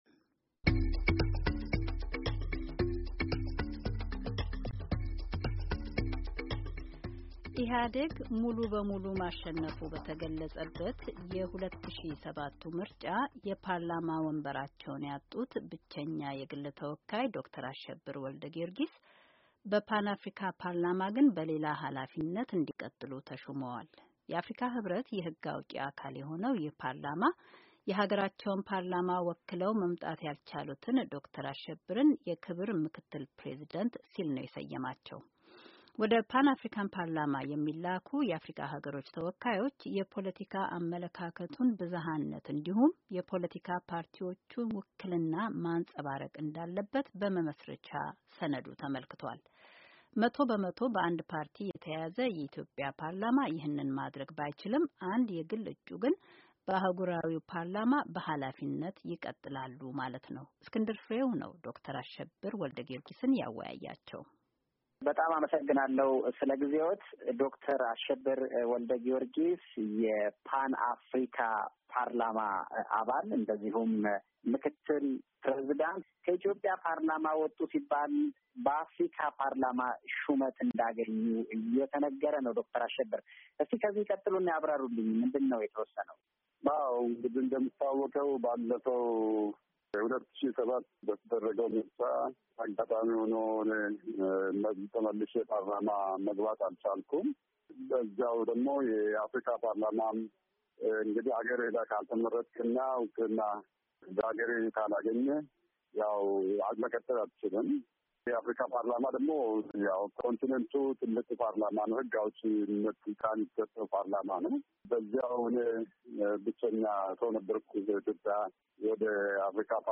ውይይት ከዶክተር አሸብር ወልደጊዮርጊስ ጋር